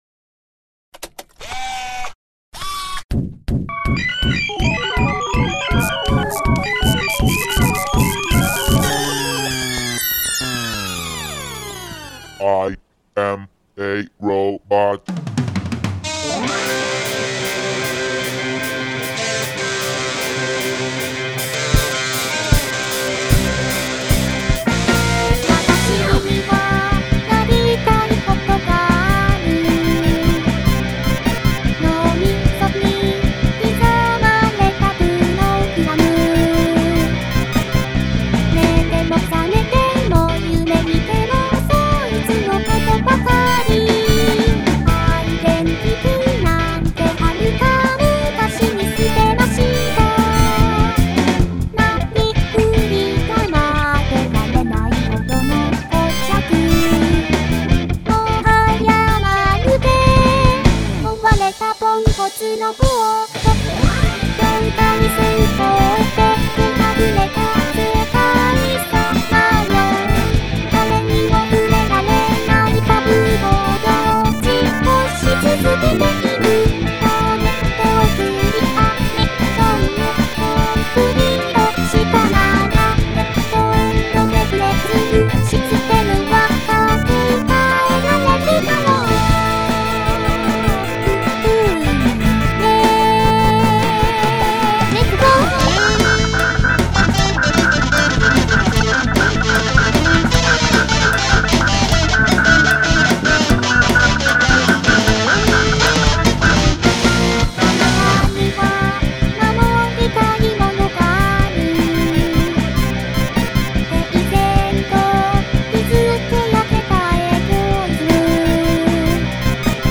Vocaloid